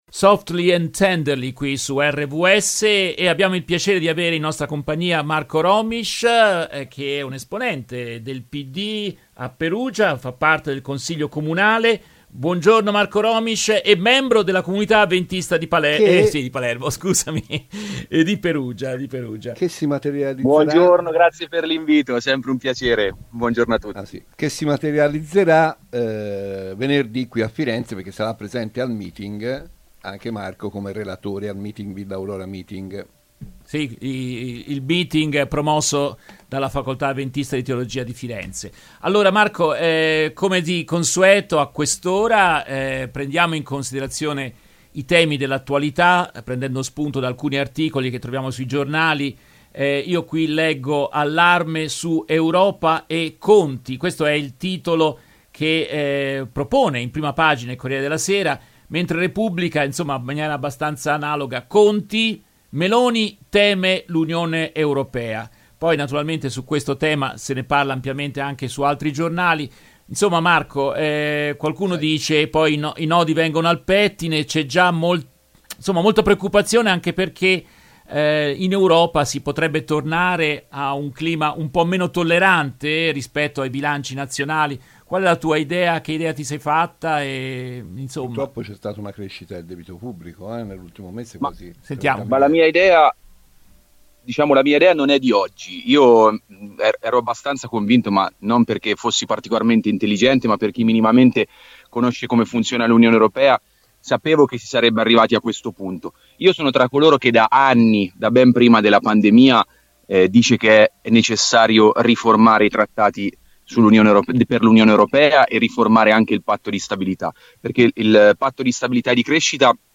Nel corso della diretta RVS del 23 agosto 2023